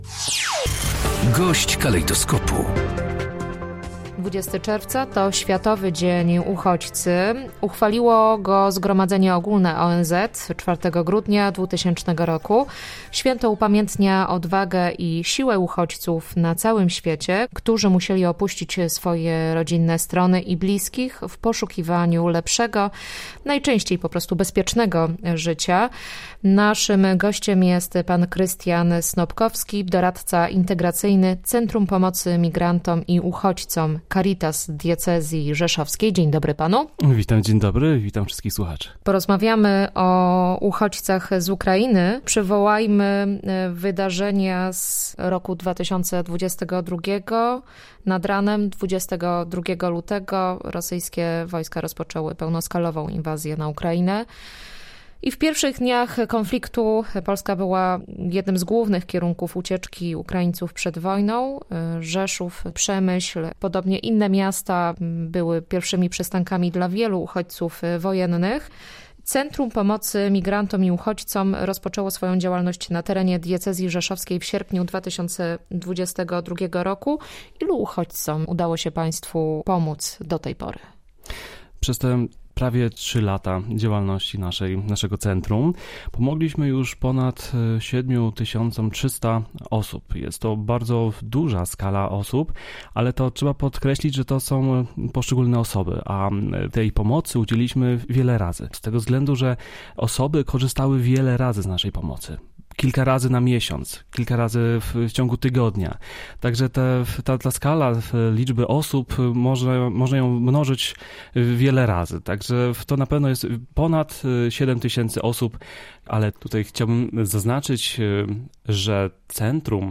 Gość dnia • Bezpłatne wsparcie otrzymało ponad 7 tys. 300 uchodźców z Ukrainy w Centrum Pomocy Migrantom i Uchodźcom Caritas Diecezji Rzeszowskiej.